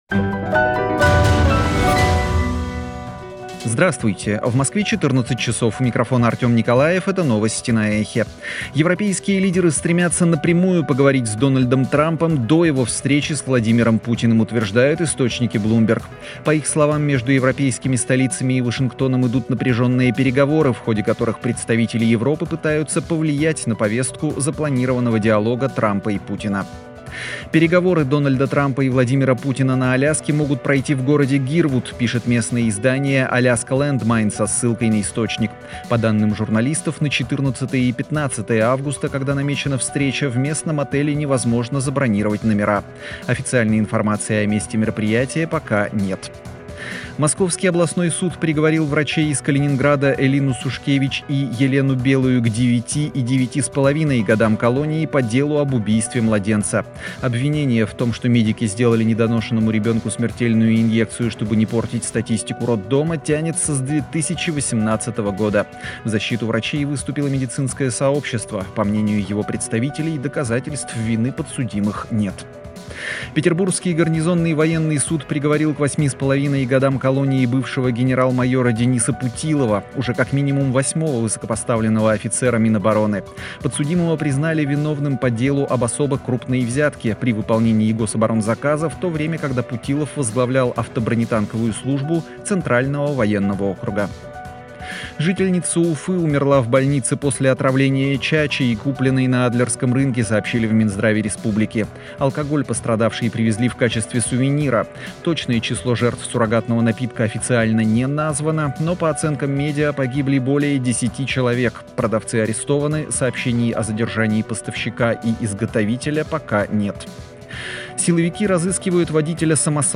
Новости 14:00